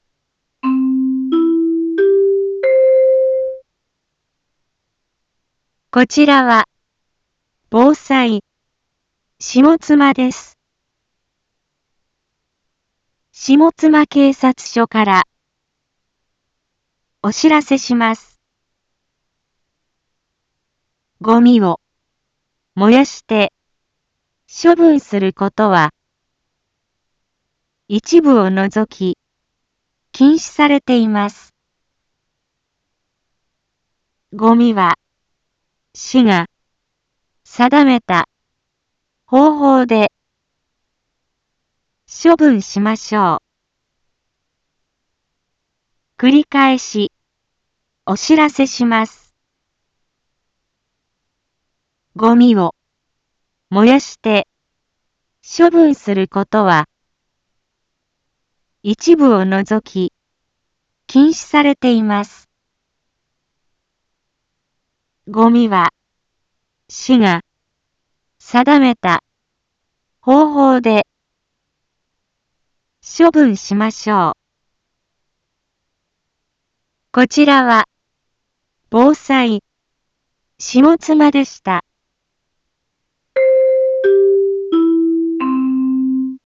一般放送情報
Back Home 一般放送情報 音声放送 再生 一般放送情報 登録日時：2023-04-24 10:01:30 タイトル：ごみの野焼き禁止（啓発放送） インフォメーション：こちらは、防災、下妻です。